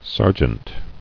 [ser·jeant]